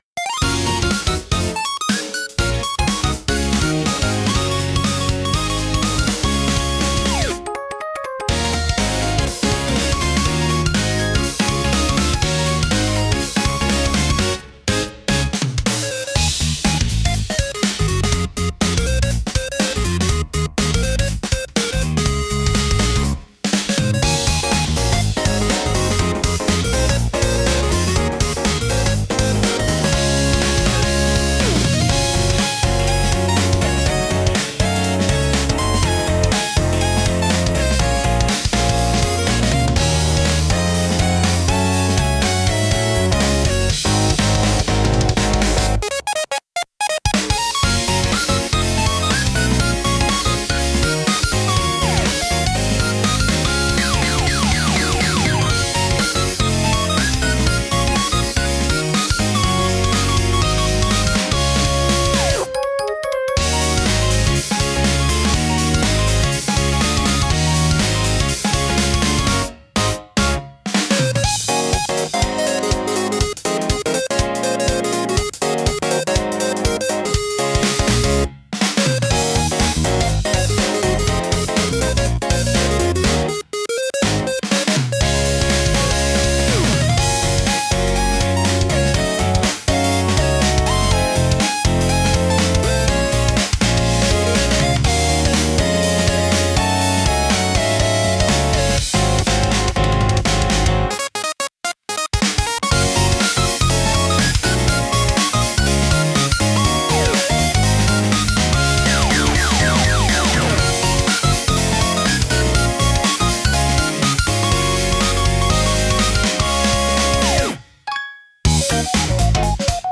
親しみやすく軽快な空気感を演出したい場面で活躍します。